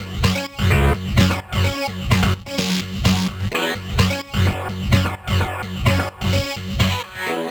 Nines2_128_F#_FX.wav